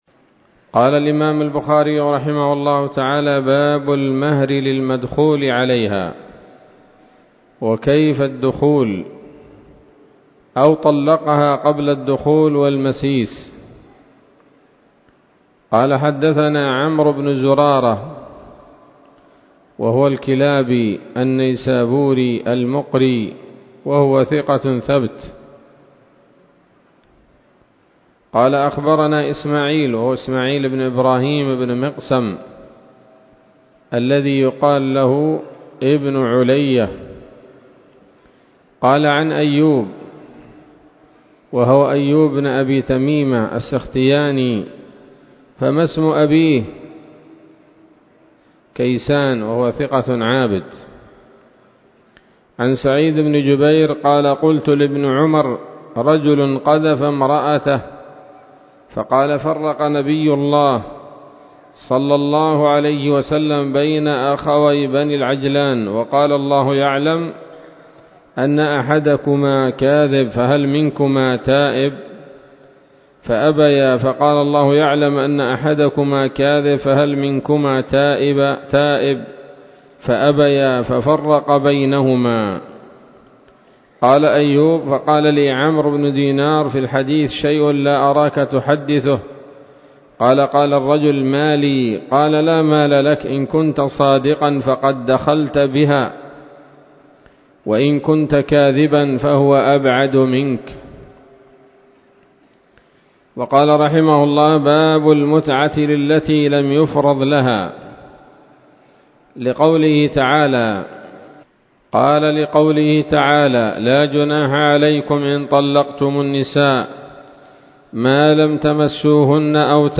الدرس الأربعون وهو الأخير من كتاب الطلاق من صحيح الإمام البخاري